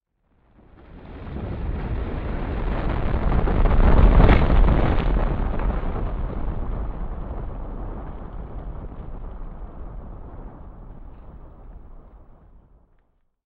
Звуки камнепада
На этой странице собраны звуки камнепада — от легкого шелеста скатывающихся камешков до грохота крупных обвалов.